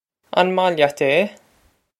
Pronunciation for how to say
On mah lyat ey?
This is an approximate phonetic pronunciation of the phrase.